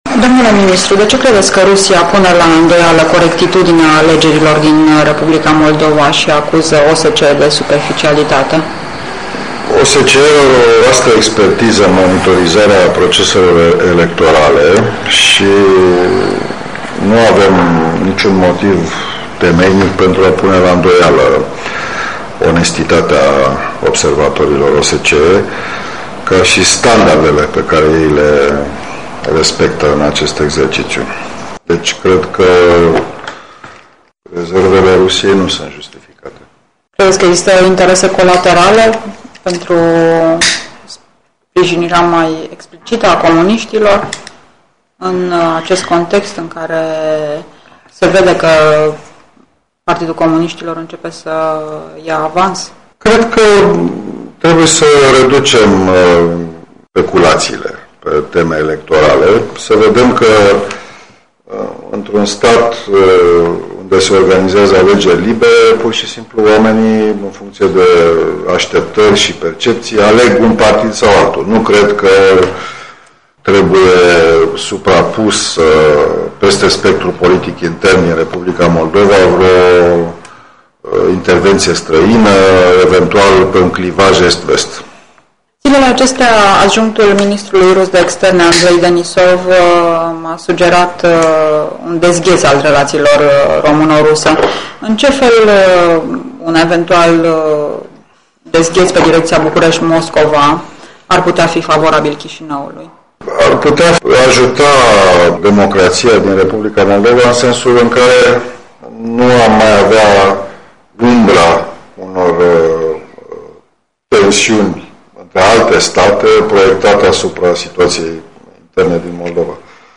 Un interviu acordat Europei Libere de ministrul de externe al României, Teodor Baconschi.